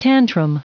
Prononciation du mot tantrum en anglais (fichier audio)